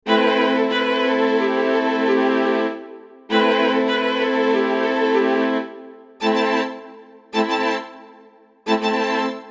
弦乐演奏 1
描述：字符串短语
Tag: 100 bpm Hip Hop Loops Strings Loops 1.59 MB wav Key : Unknown